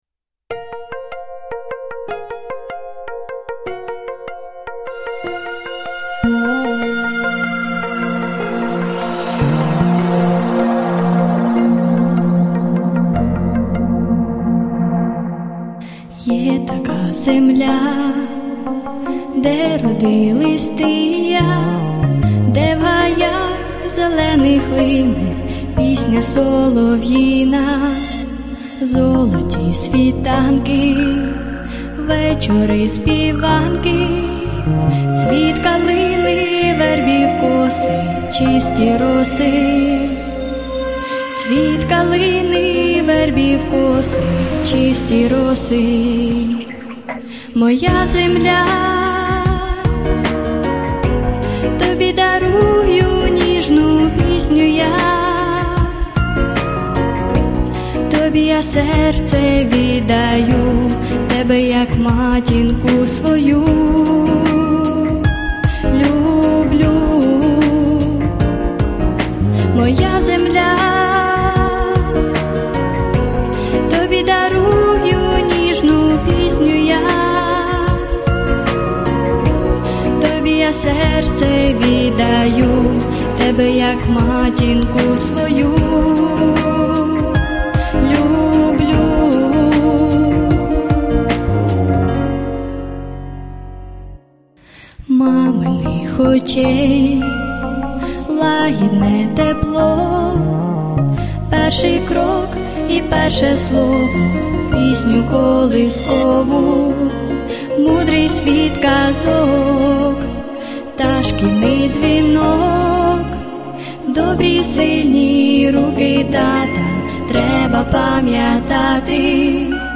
Концертна
Помірна
Соло
Лірика.